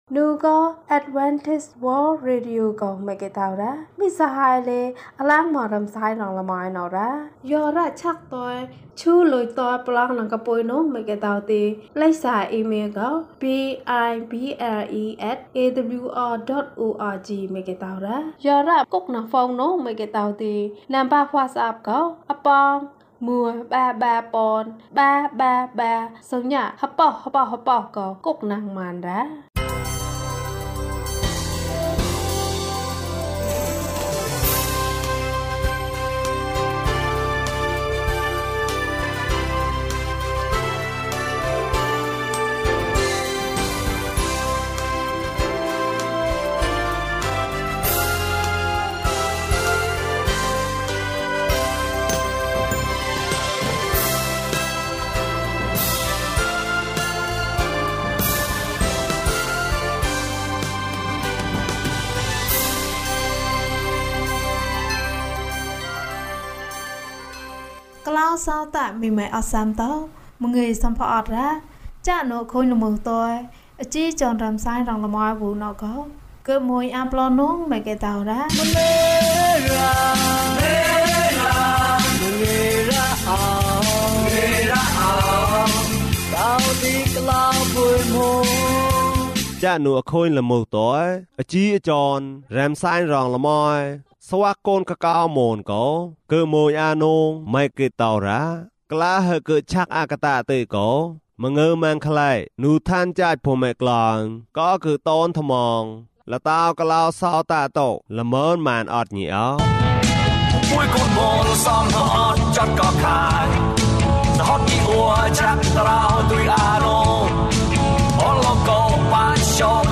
ငါ့ဆုတောင်းသံကို ဘုရားသခင် နားထောင်ပါ။ အပိုင်း ၁ ကျန်းမာခြင်းအကြောင်းအရာ။ ဓမ္မသီချင်း။ တရားဒေသနာ။